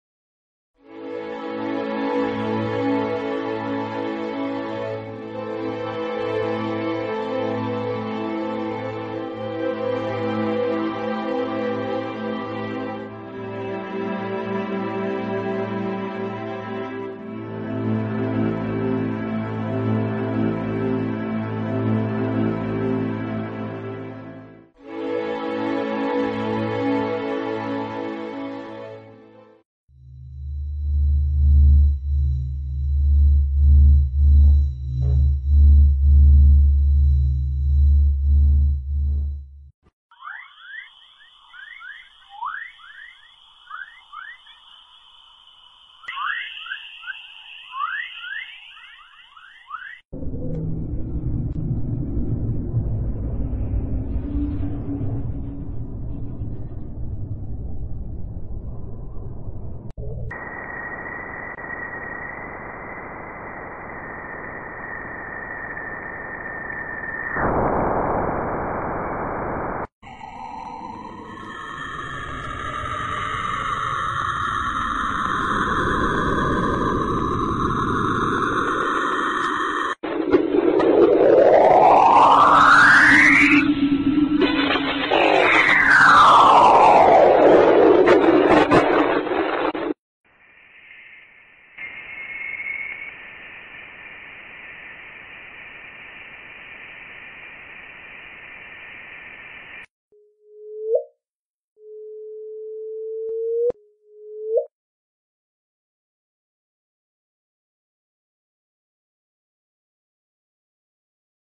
Space Sounds
(Warning: Loud Audio)
Scary sounds of Space! (64 kbps).mp3